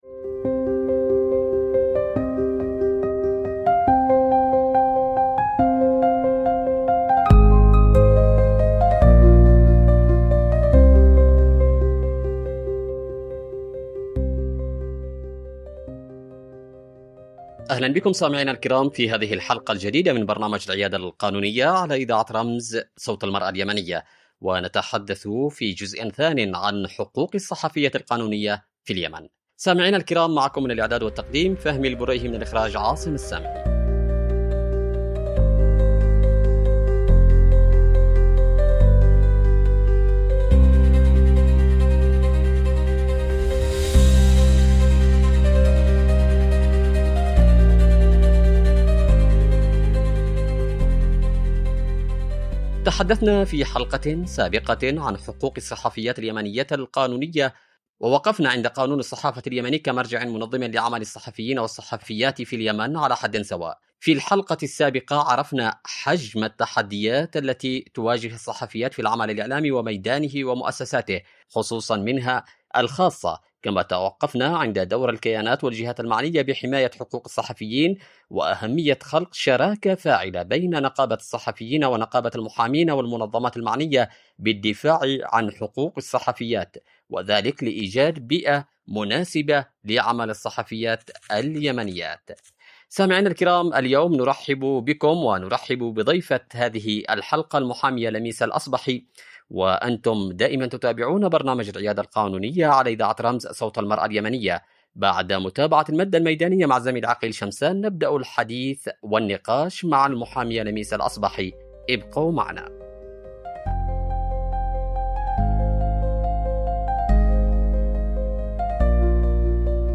في نقاش قانوني يتناول حقوق الصحفيات في اليمن، بين ما يكفله القانون من حماية وضمانات، وما يواجههن من عراقيل في بيئة العمل الإعلامي.
عبر إذاعة رمز